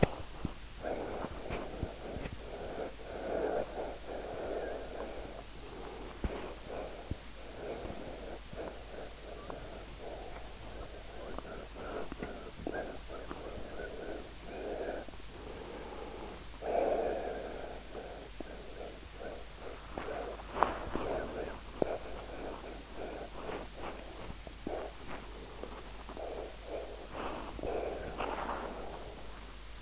investigation of sub vocal silent reading using a piezoelectric microphone just below the adam’s apple.